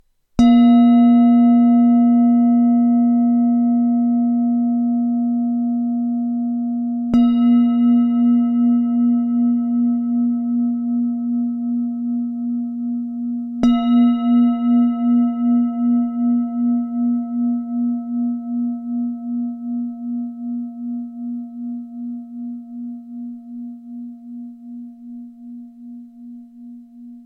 Matná tibetská mísa A#3 17,5cm
Nahrávka mísy úderovou paličkou:
Jde o ručně tepanou tibetskou zpívající mísu dovezenou z Nepálu.